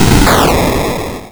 explosionsoundslite / sounds / bakuhatu97.wav
bakuhatu97.wav